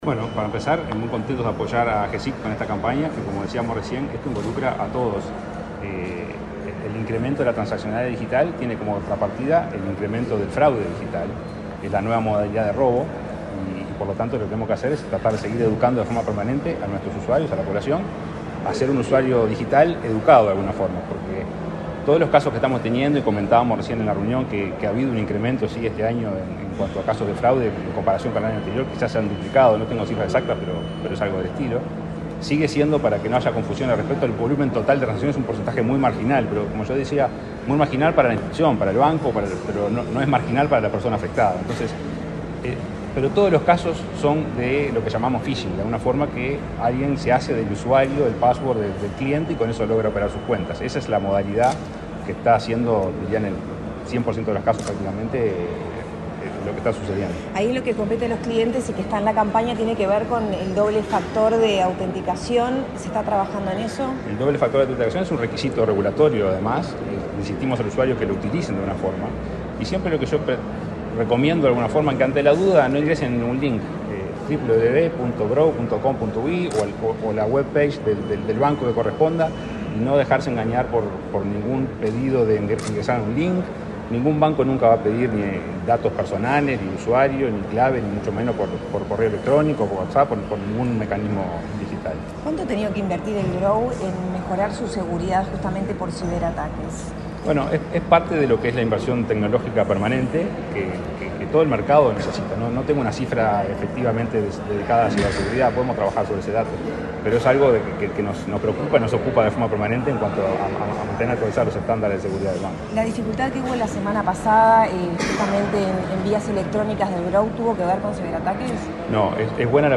El presidente del Banco República, Salvador Ferrer, dialogó con la prensa luego de participar de la presentación de la campaña Seguro te Conectas.